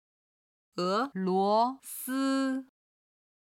俄罗斯　(É luó sī)　ロシア